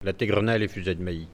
Elle provient de Saint-Gervais.
Catégorie Locution ( parler, expression, langue,... )